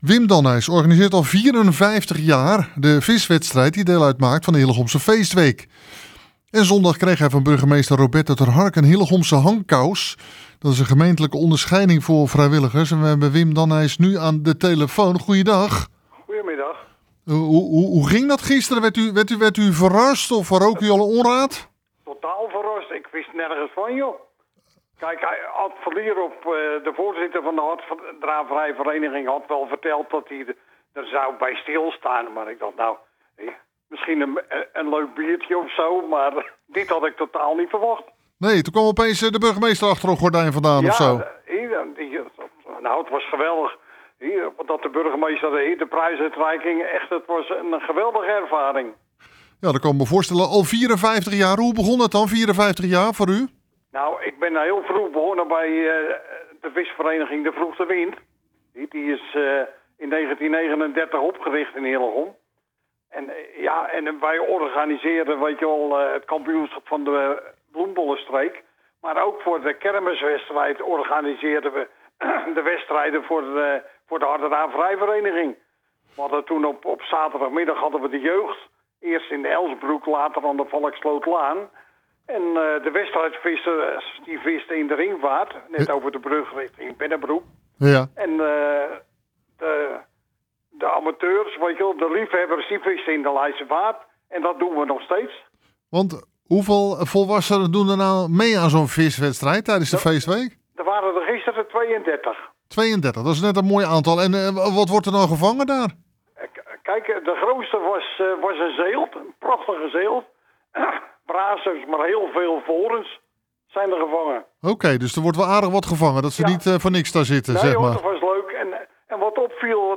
Hieronder het radiointerview: